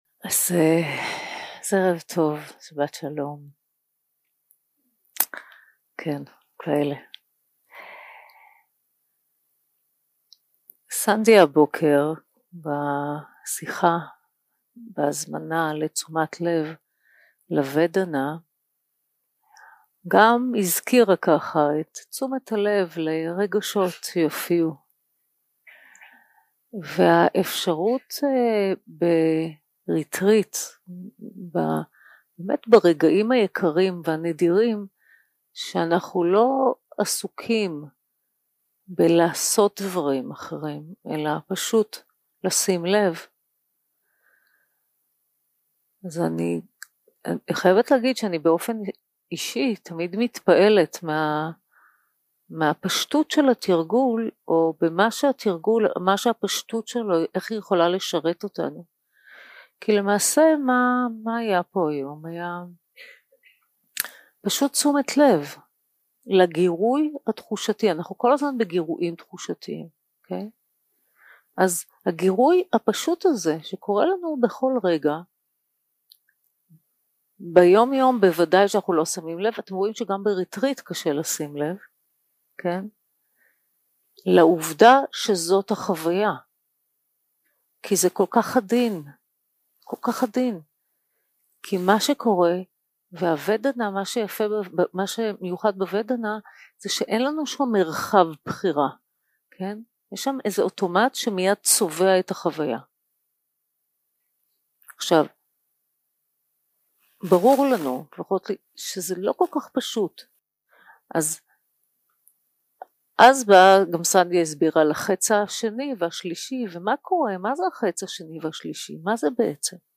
סוג ההקלטה: שיחות דהרמה שפת ההקלטה